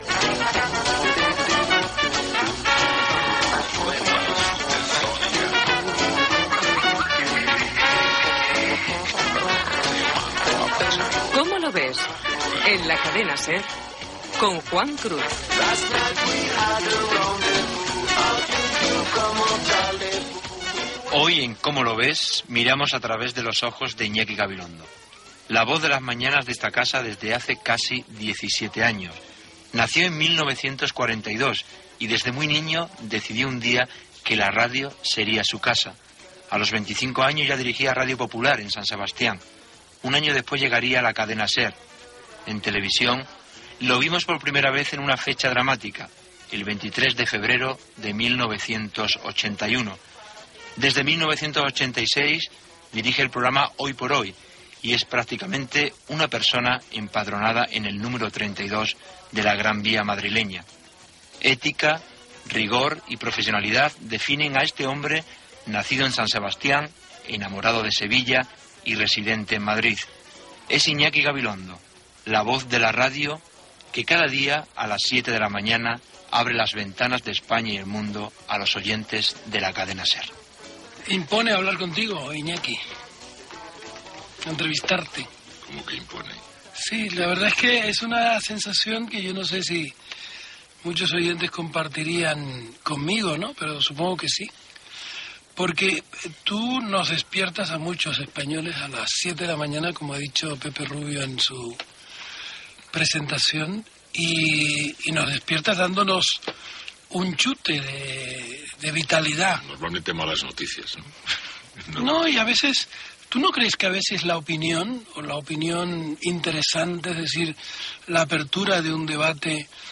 Careta del programa, perfil biogràfic d'Iñaki Gabilondo i entrevista sobre la seva trajectòria professional